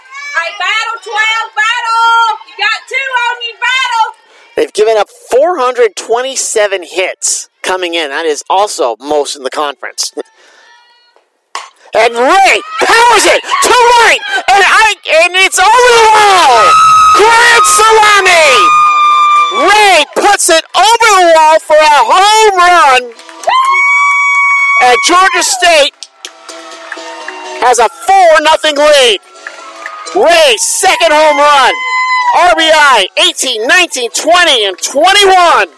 A collection of my play-by-play clips.
Here is the call of that blast.